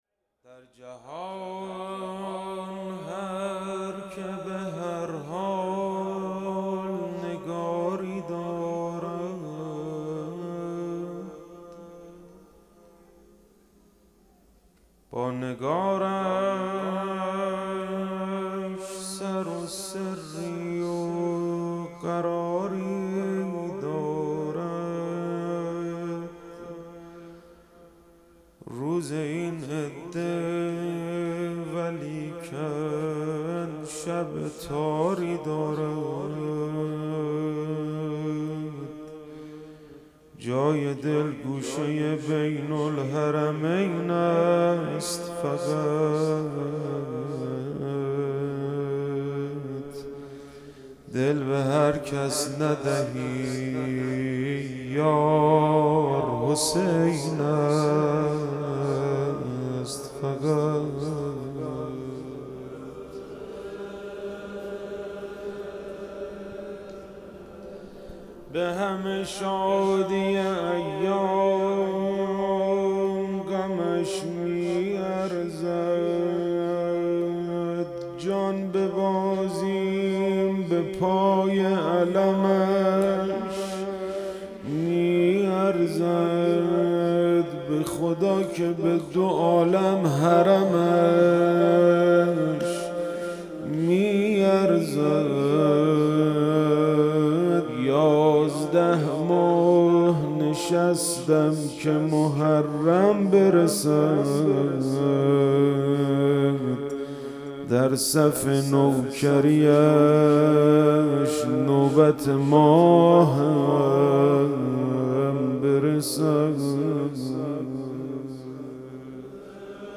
روضه شهادت امام حسن مجتبی(ع)